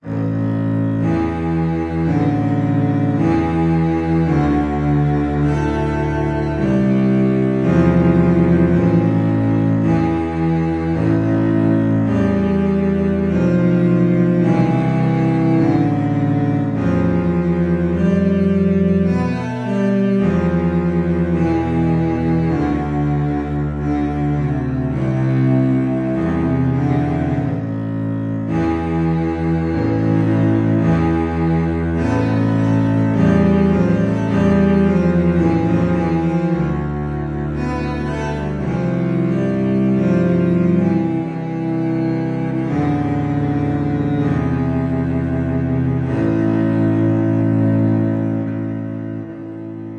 卡通之声" 准备1
描述：为优秀的益智游戏录制和处理的语音
标签： 卡通 语音 游戏 短语 准备
声道立体声